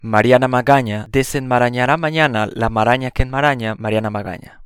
Embora ela seja um pouco curiosa para os falantes de português, sua pronúncia não tem nenhum mistério: é a mesma do nosso “nh”.